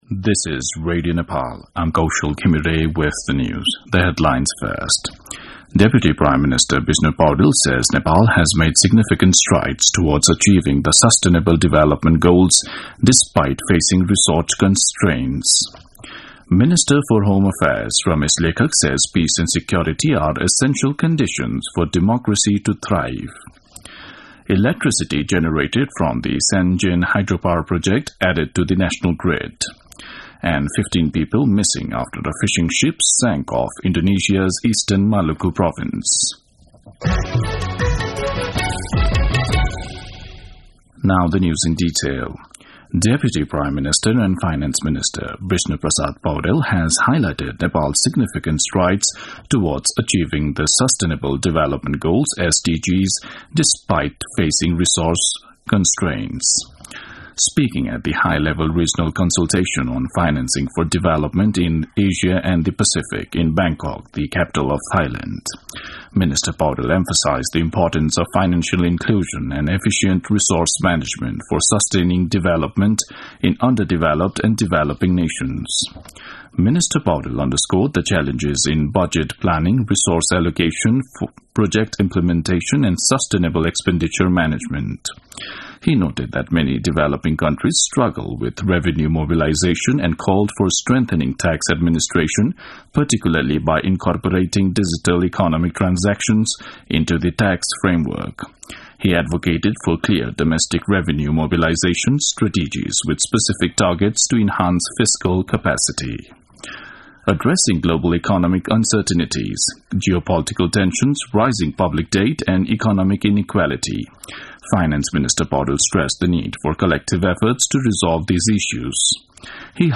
दिउँसो २ बजेको अङ्ग्रेजी समाचार : ३ पुष , २०८१
2-pm-english-news-1-11.mp3